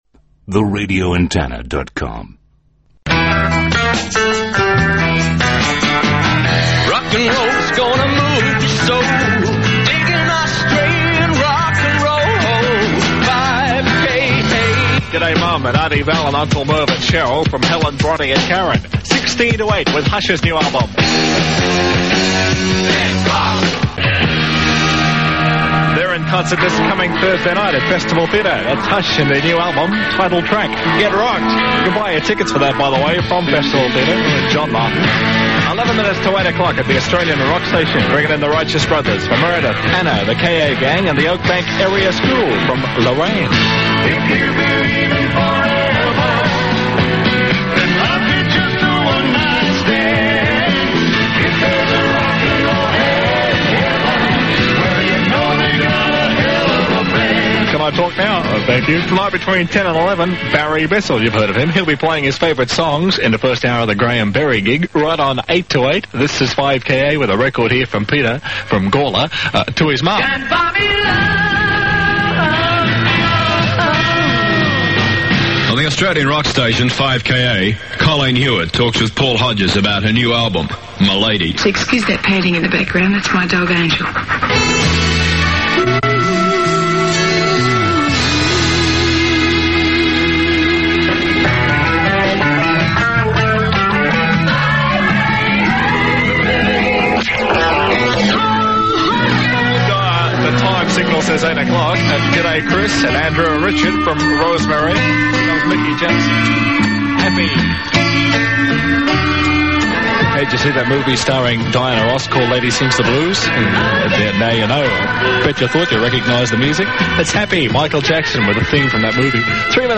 RA Aircheck
What a team at 5KA 70’s radio at it’s best